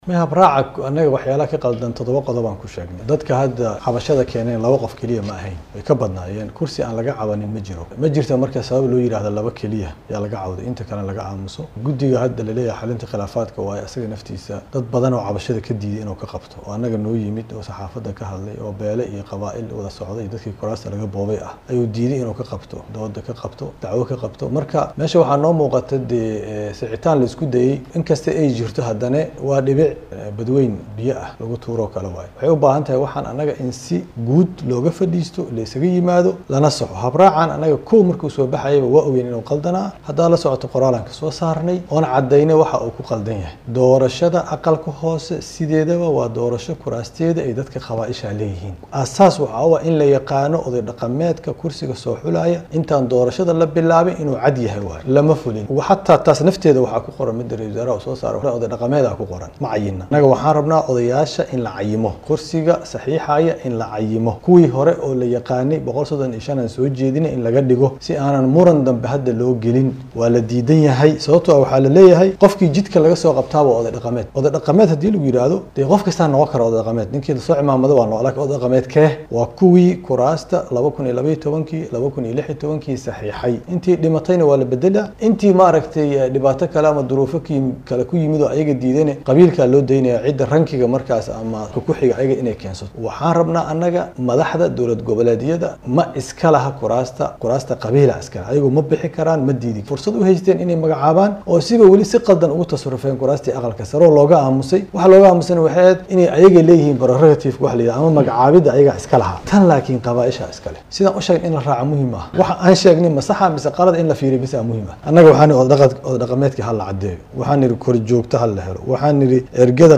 Siyaasiga Cabdiraxmaan Cabdishakuur Warsame kana mid ah Midowga Musharrixiinta dalka Soomaaliya ayaa ku eedeeyay Madaxda dowlad Goboleedyada inay qayb ka yihiin boobka lagu haayo doorashooyinka Golaha Shacabka ee Baarlamaanka wadankaasi. Xilli uu wareysi siiyay teleefishinka maxalliga ee Universal ayuu sheegay in dowlad Goboleedyada iyo dad kale ay diidan yihiin in la saxo doorashada, islamarkaana ay ku andacoonayaan inay sidaan ku socoto la iskana daayo, iyaga oo aaminsan in Farmaajo uusan markale xukunka kusoo laabaneyn.